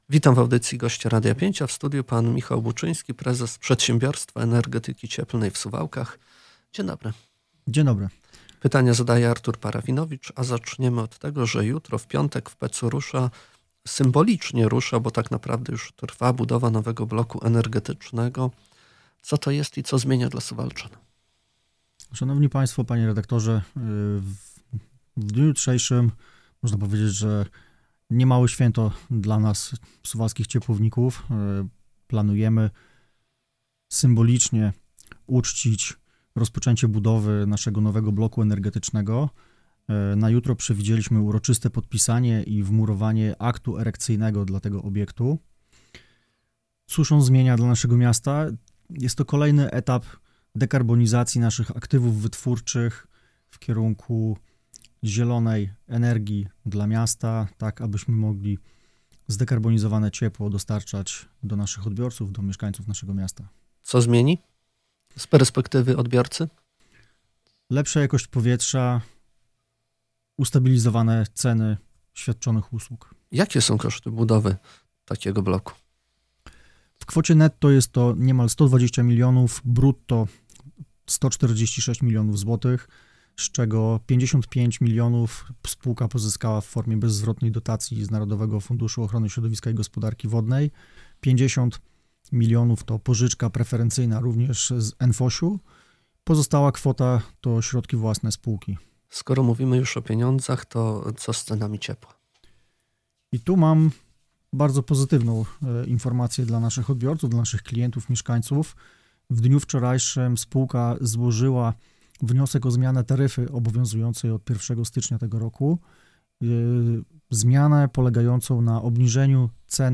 Pełne nagranie rozmowy z gościem poniżej: